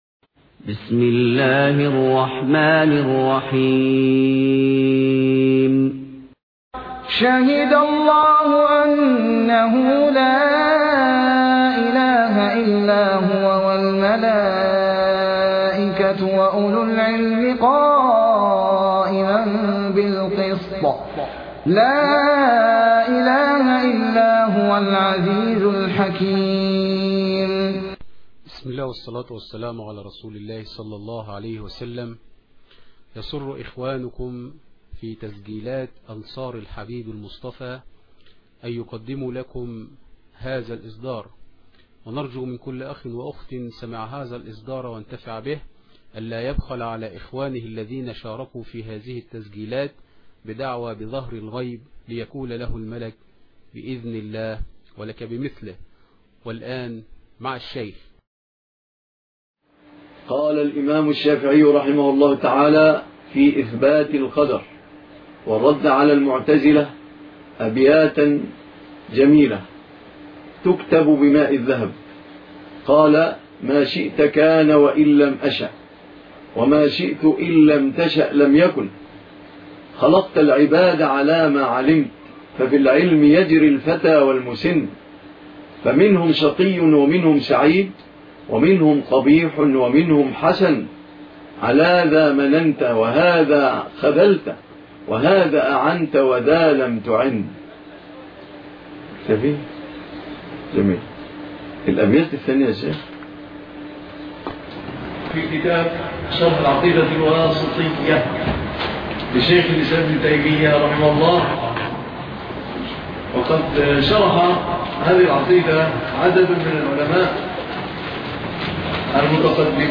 الدرس الثاني ( شرح العقيدة الواسطية )